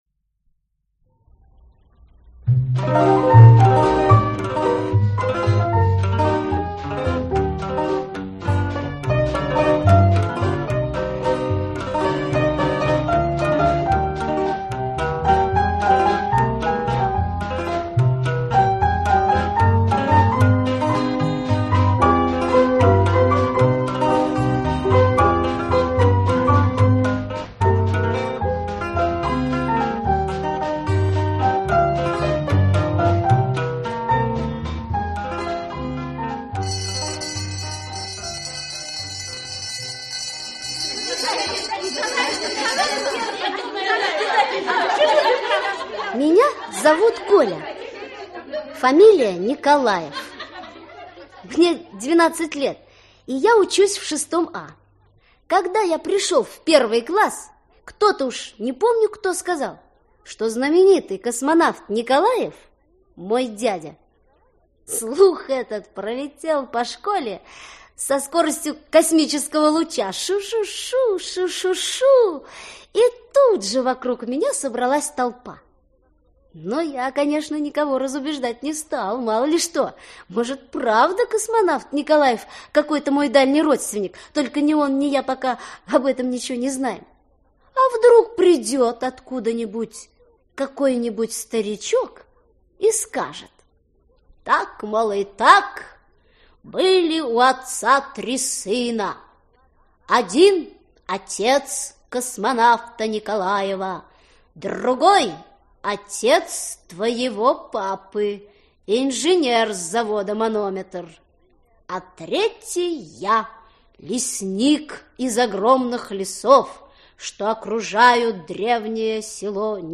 Аудиокнига Оля, Коля и другие | Библиотека аудиокниг